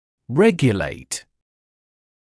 [‘regjəleɪt][‘рэгйэлэйт]регулировать